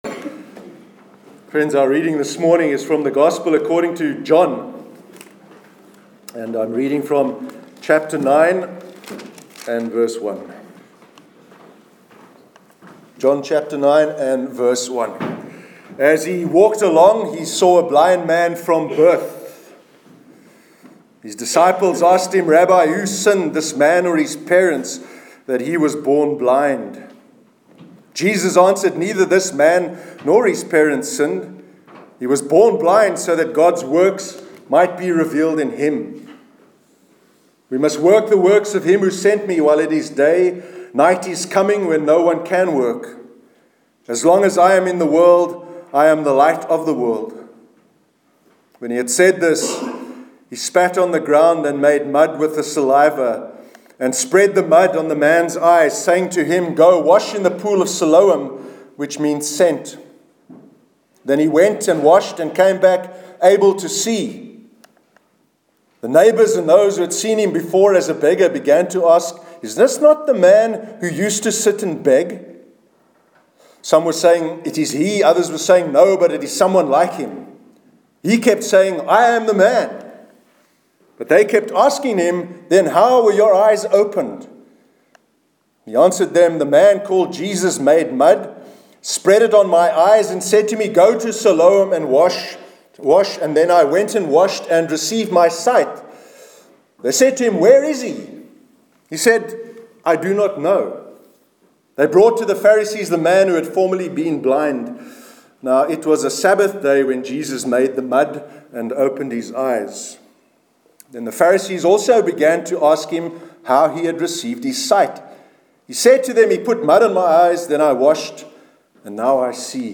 Sermon on how to be a blessing to others- 22 January 2017